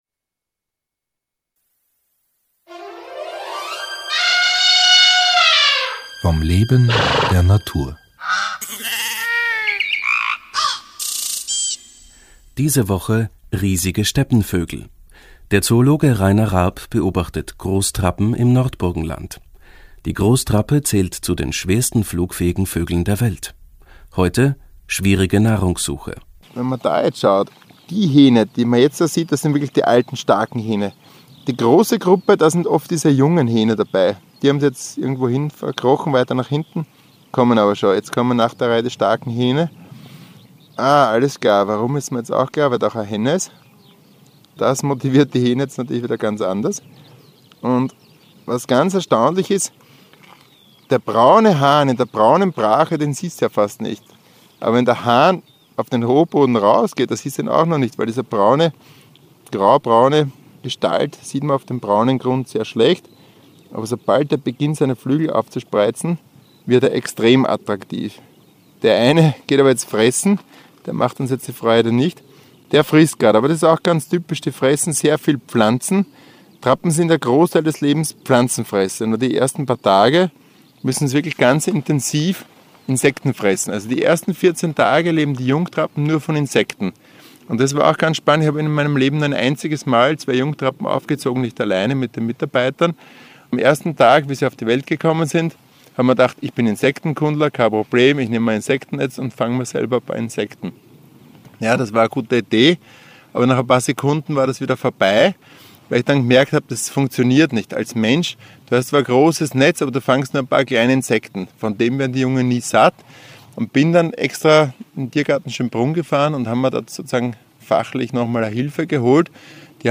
Interviews
broadcasted on the radio